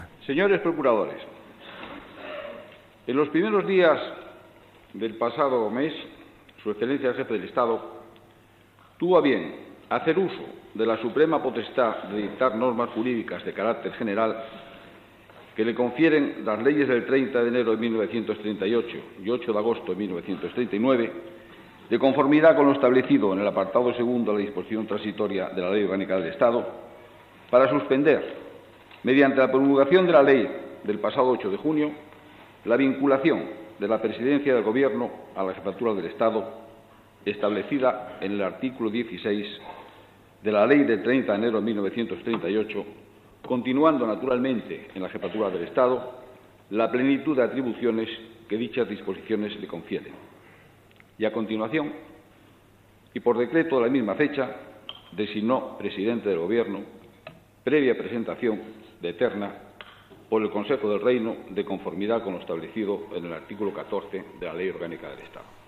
Discurs del president del govern, almirall Luis Carrero Blanco, al ple de les Cortes (havia estat nomenat el 9 de juny de 1973).
Informatiu